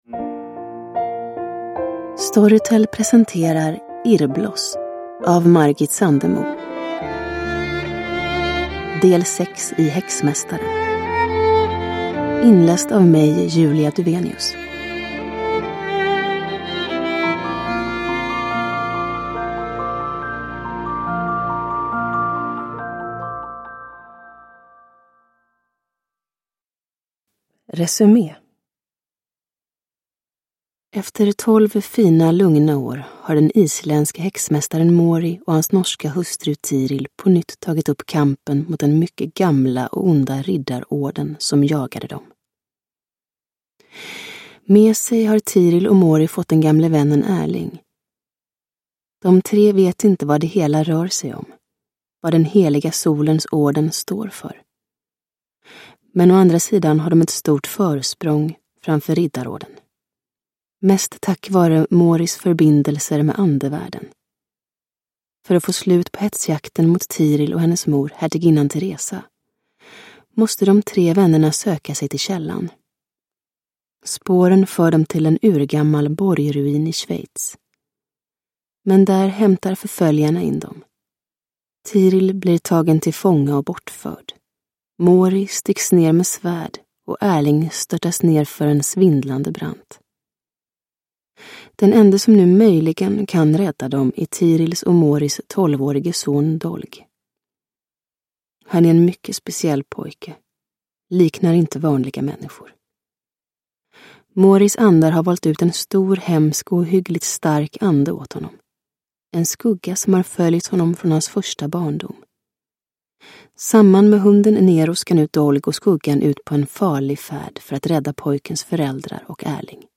Irrbloss – Ljudbok – Laddas ner
Uppläsare: Julia Dufvenius